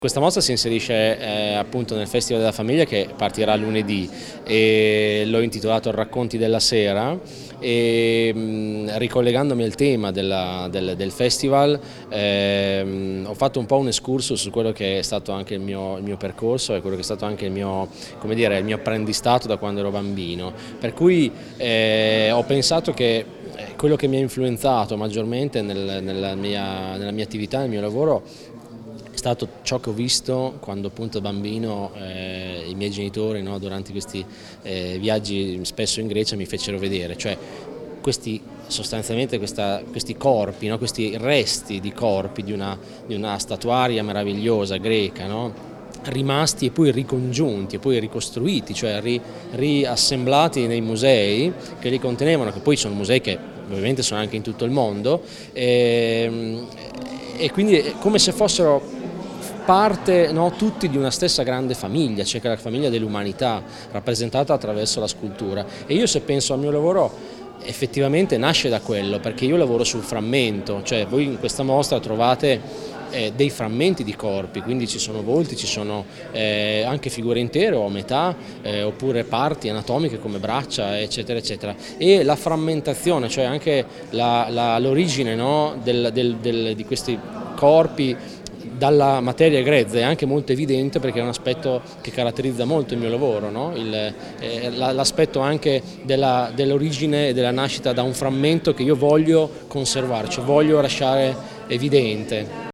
Immagini e intervista a cura dell'ufficio stampa.